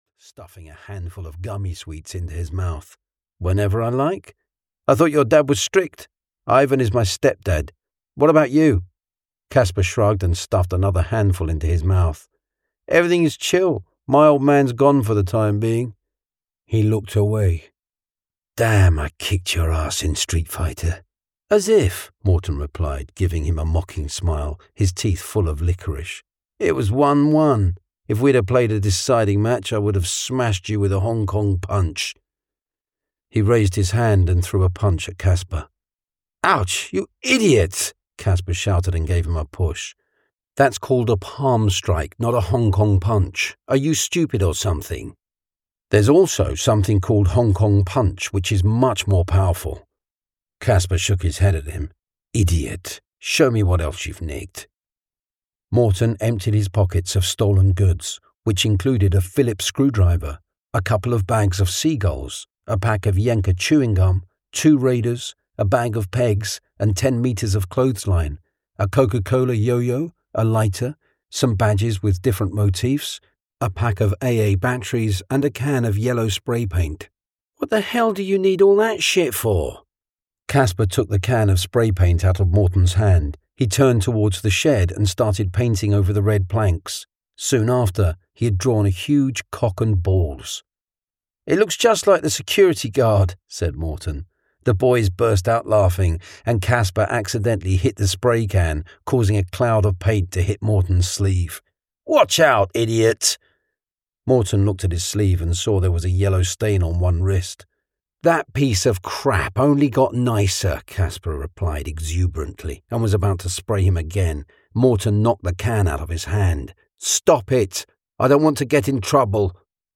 The Depths (EN) audiokniha
Ukázka z knihy